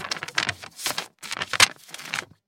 Paper Shuffling
Pages of paper being shuffled and sorted on a desk with crisp handling sounds
paper-shuffling.mp3